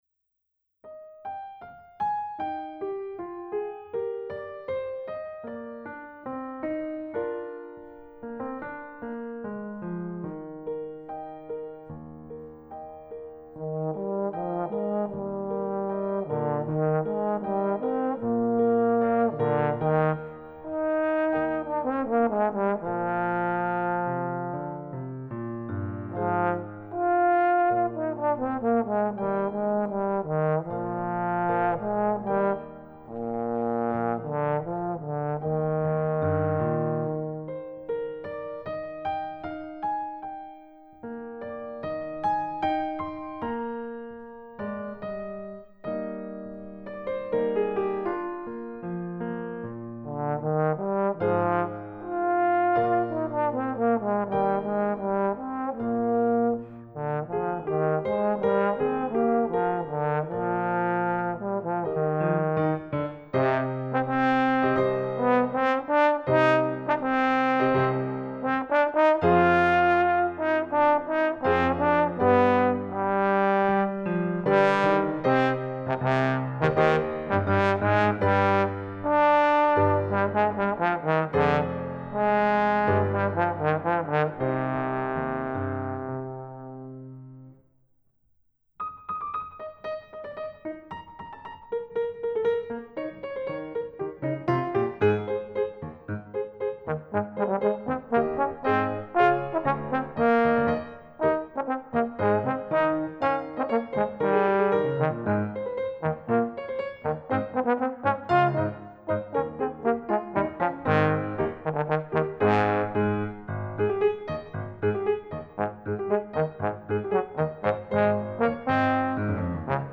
Expansive – Carefree [3:40]